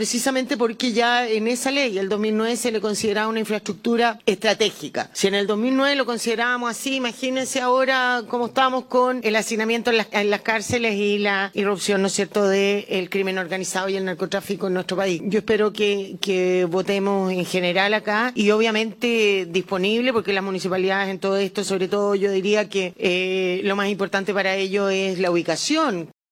Por su parte, la senadora por Tarapacá, Luz Ebensperger, respaldó la medida y recordó que la calificación de la infraestructura penitenciaria como estratégica ya estaba vigente desde 2009.
CUNA-SENADORA-.mp3